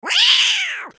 Angry Cat Hq Bouton sonore